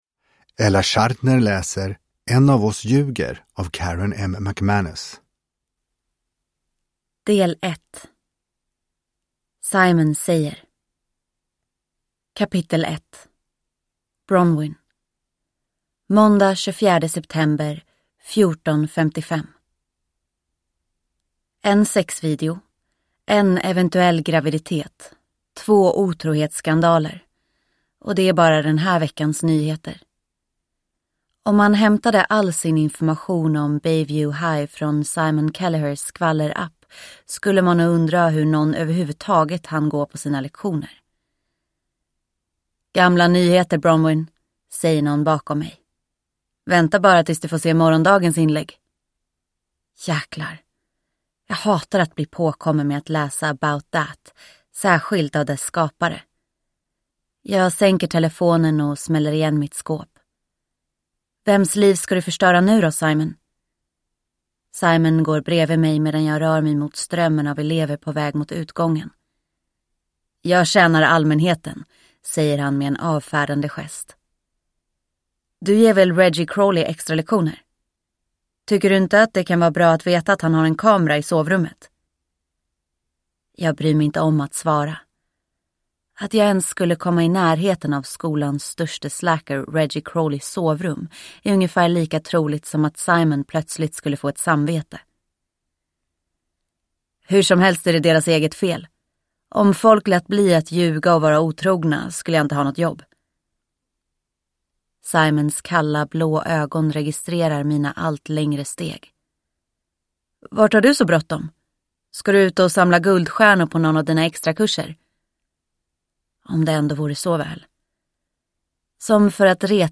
En av oss ljuger – Ljudbok – Laddas ner